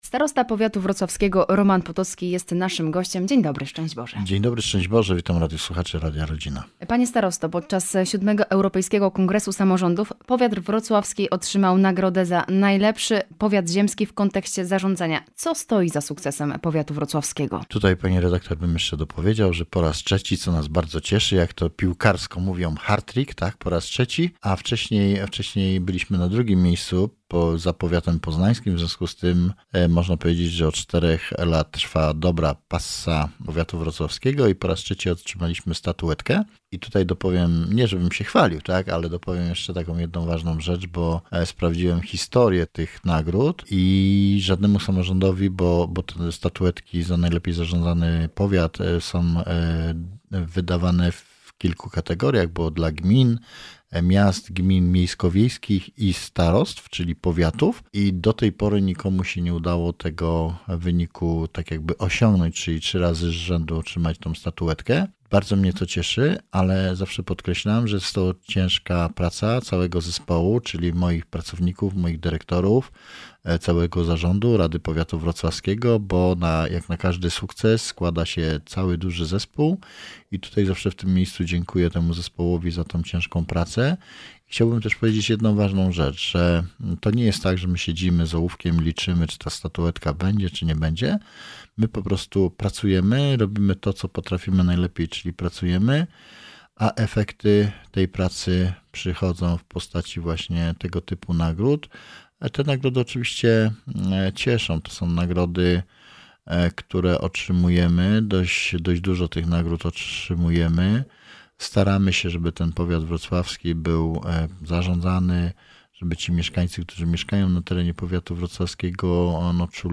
O nagrodzie za najlepiej zarządzany powiat, etapie rozbudowy Starostwa Powiatowego, nowym punkcie udzielania porad w Czernicy oraz pikniku polsko-ukraińskim rozmawiamy z Romanem Potockim – Starostą Powiatu Wrocławskiego.
01_Starosta-Roman-Potocki-rozmowa_IB.mp3